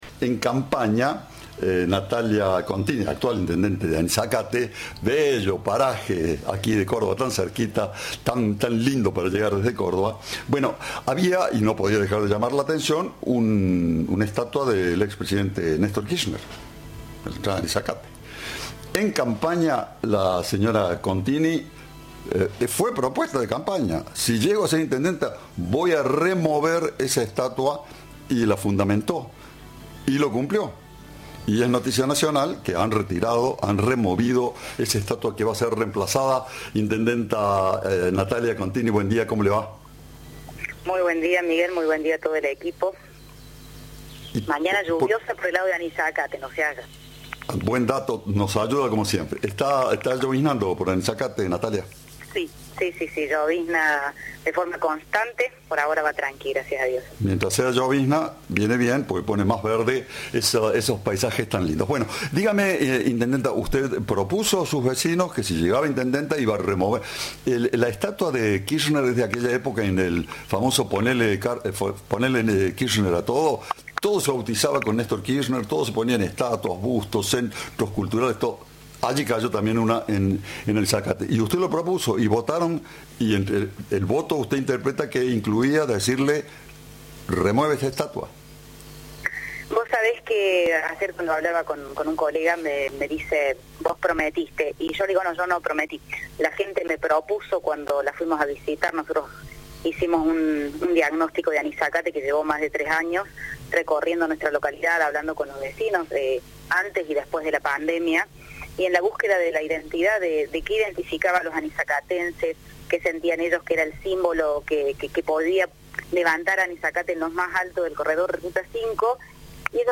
La intendenta Natalia Contini explicó a Cadena 3 que el homenaje al expresidente "no representaba" a los vecinos de la localidad.
Entrevista